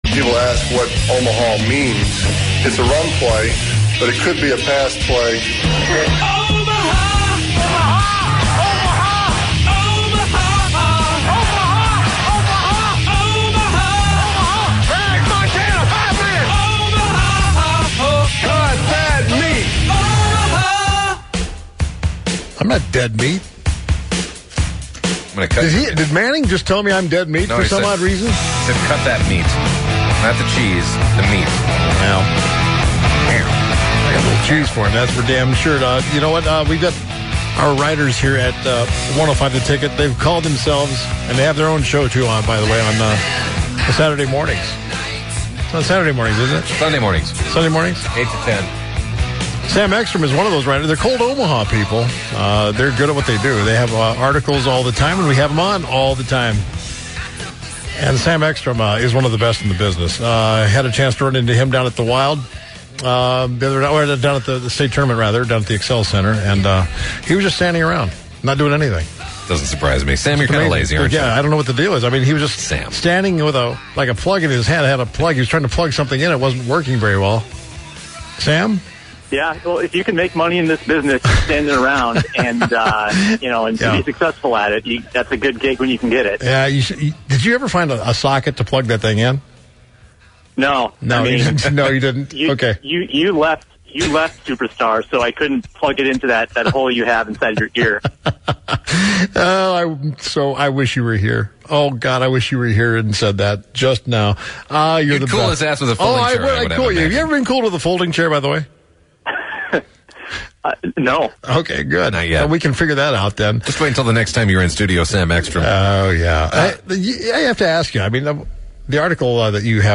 live from Fort Myers to discuss Twins Spring Training. Plus, they play a little NFL trivia with listeners.